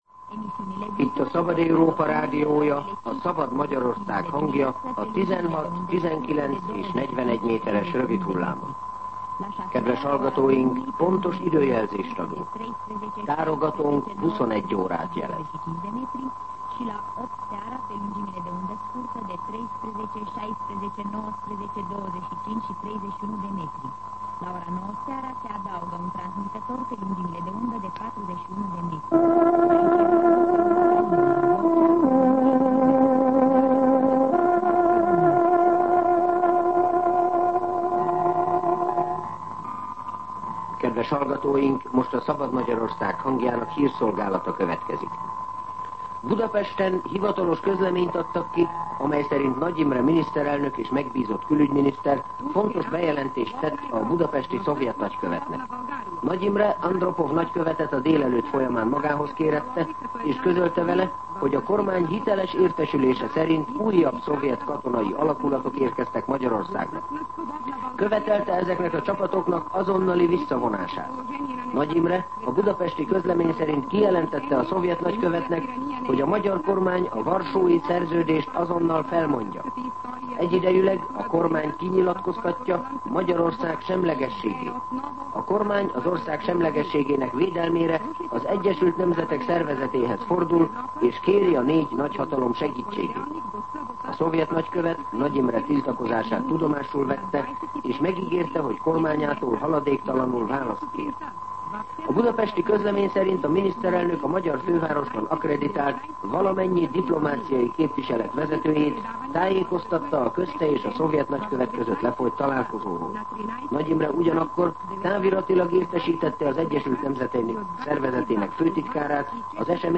21:00 óra. Hírszolgálat